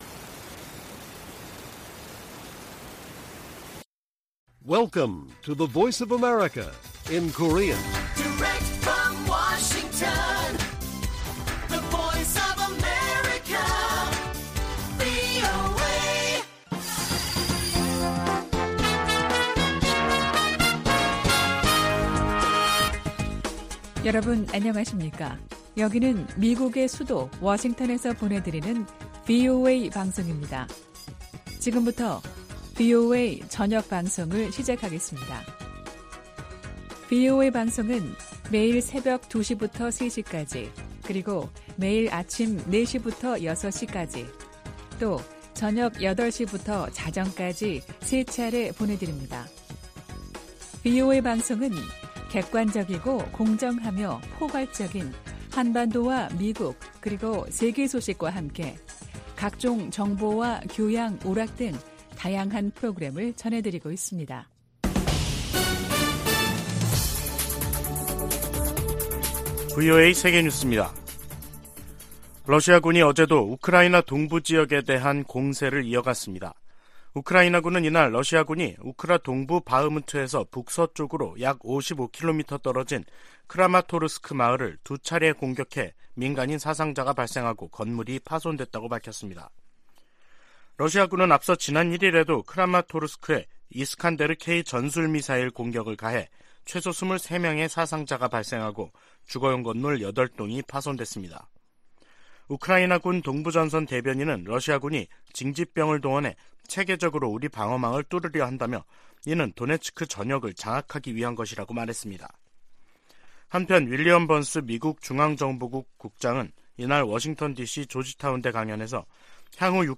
VOA 한국어 간판 뉴스 프로그램 '뉴스 투데이', 2023년 2월 3일 1부 방송입니다. 미국과 한국 외교장관들은 오늘 워싱턴에서 열리는 회담에서 북한 문제는 물론 경제와 외교 등 다양한 주제를 다룰 것이라고 밝혔습니다. 미국 백악관은 북한의 미한 연합훈련 비난에 대해 북한에 대한 적대적 의도가 없는 통상적인 훈련이라고 반박했습니다.